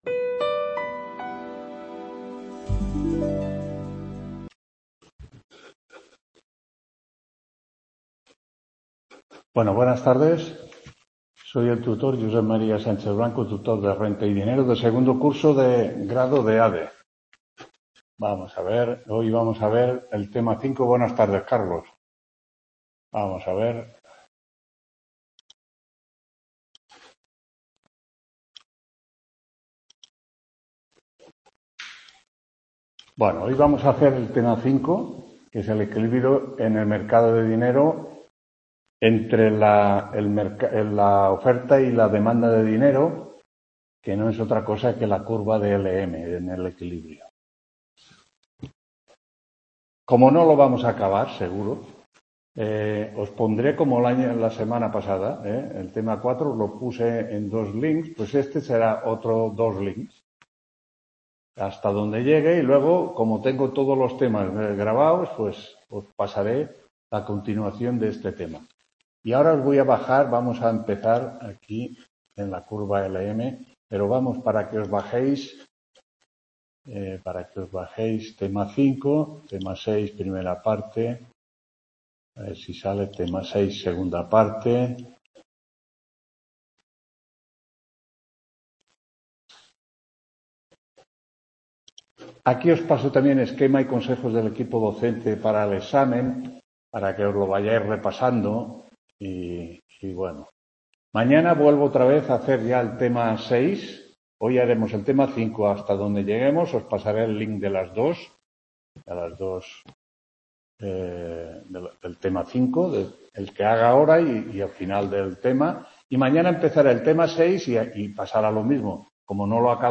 9ª TUTORÍA RENTA Y DINERO LA CURVA LM (Nº 292) TUTOR…